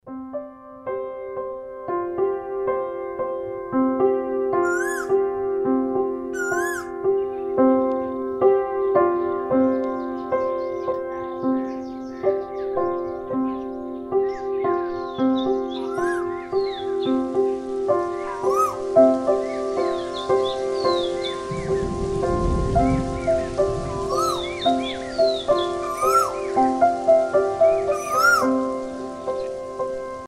birds in the rain